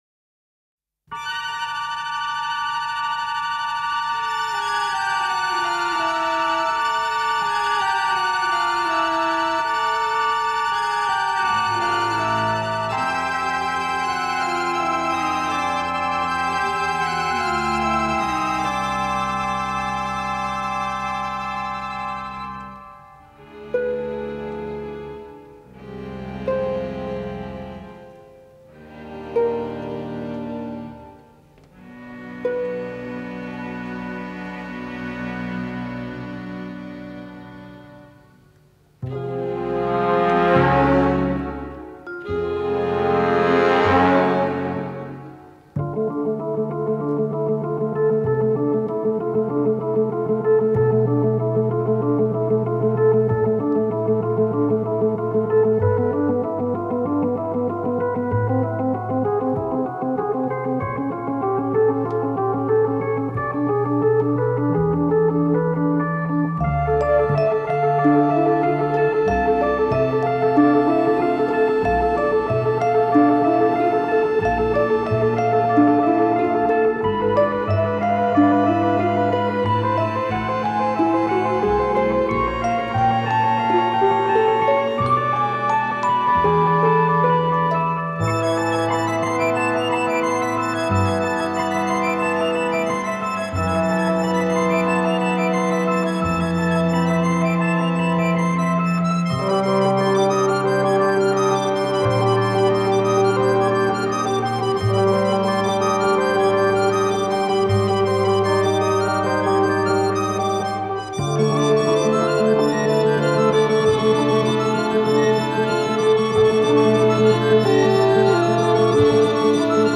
angustiante y uniforme partitura